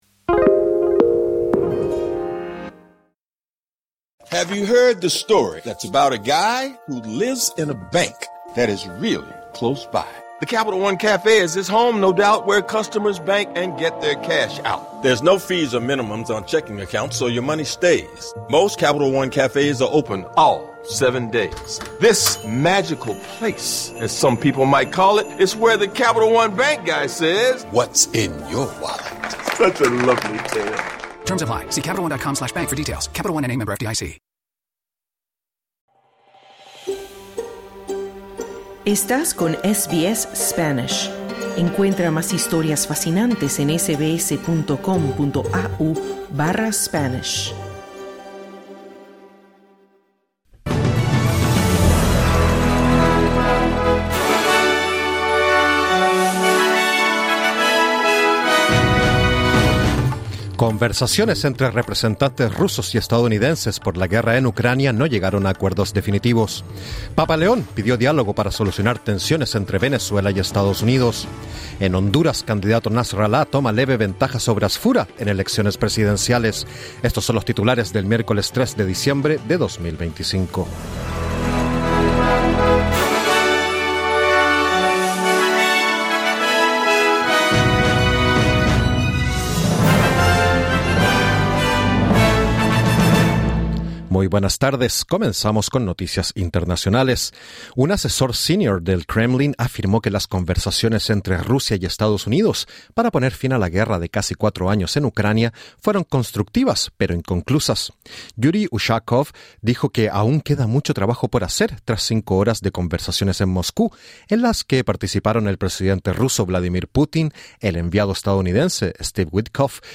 Conversaciones entre representantes rusos y estadounidenses, que incluyeron a Vladimir Putin, por la guerra en Ucrania, no llegaron a acuerdos definitivos sobre un posible cese al fuego. Escucha el boletín de noticias del miércoles 3 de diciembre 2025.